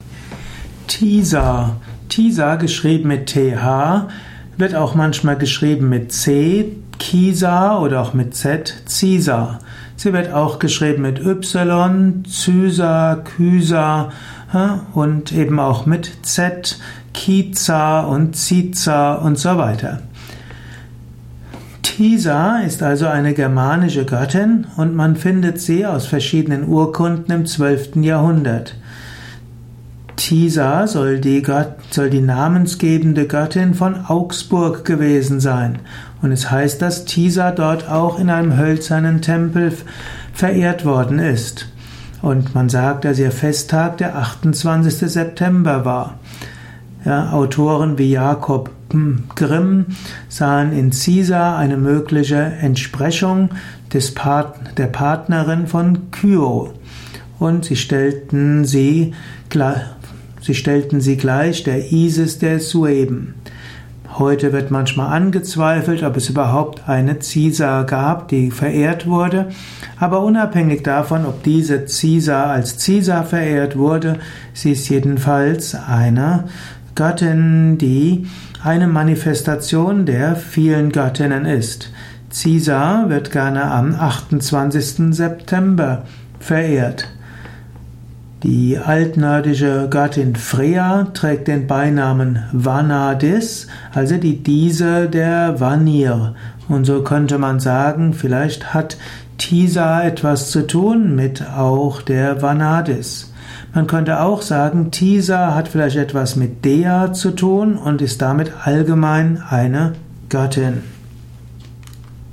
Ein Vortrag über Thisa, einer nordischen Göttin. Mit Überlegungen über die Bedeutung von Thisa in der nordischen Mythologie, im nordischen Pantheon.
Dies ist die Tonspur eines Videos, zu finden im Yoga Wiki.